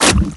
metroidattach.ogg